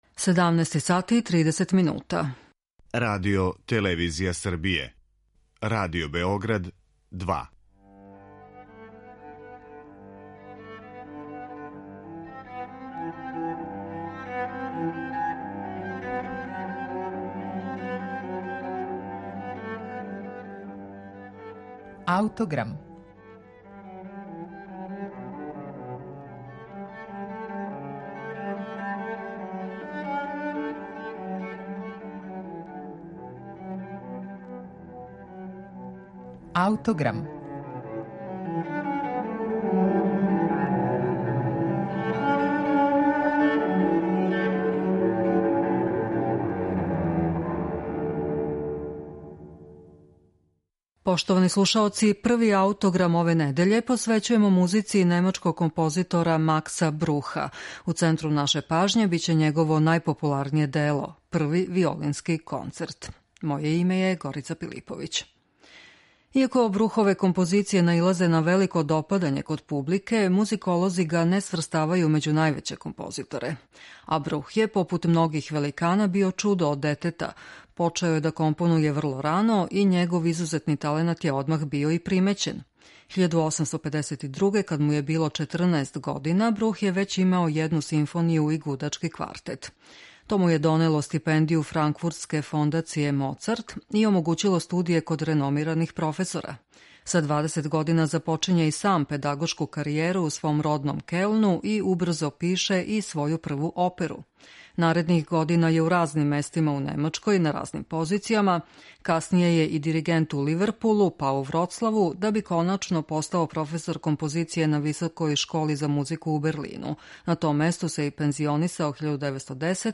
Први Концерт за виолину и оркестар, од укупно три, Макса Бруха је међу најпопуларнијим делима те врсте и данас га најчешће везујемо за име овог немачког композитора.